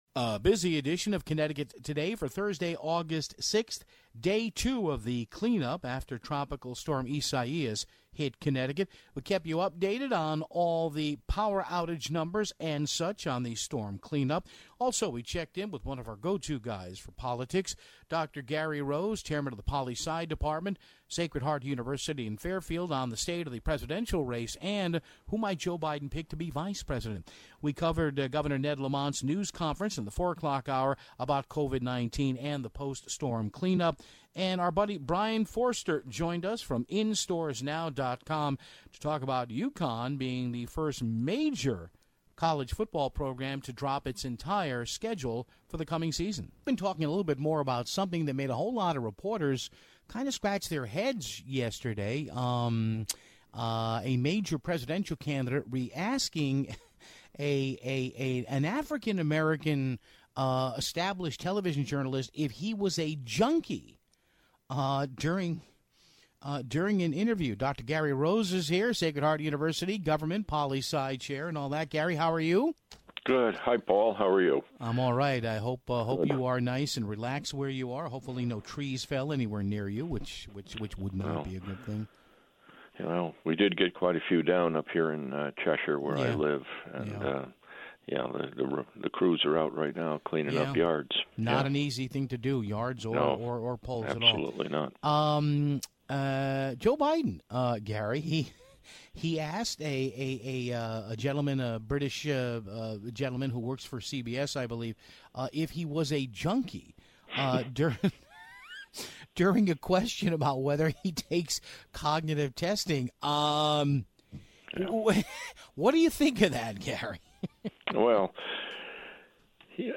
We carried Governor Ned Lamont's meeting with the media